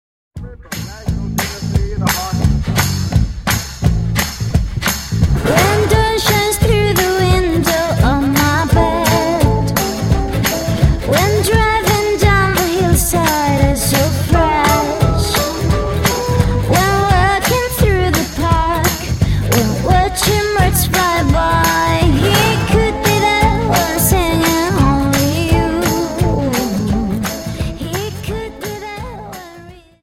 Dance: Jive 43